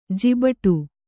♪ jibaṭu